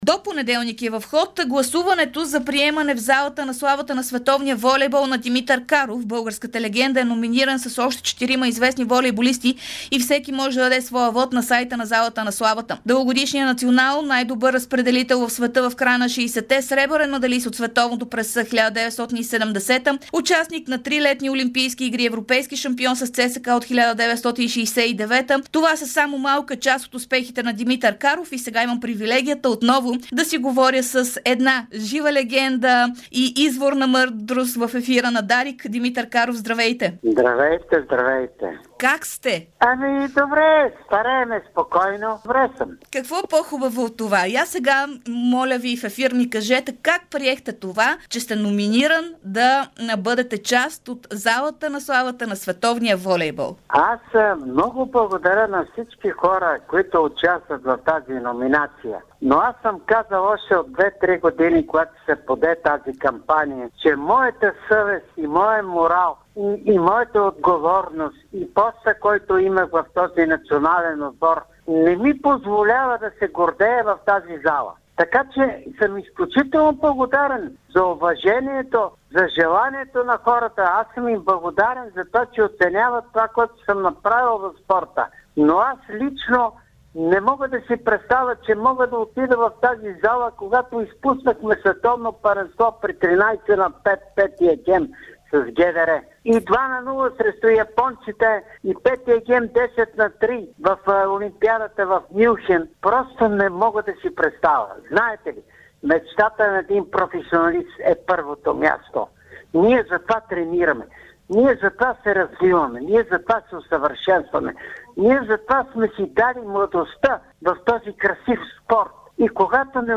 Легендата на българския волейбол – Димитър Каров, даде ексклузивно интервю пред Дарик радио и dsport, в което говори за своето номиниране за Залата на... (09.04.2025 13:10:45)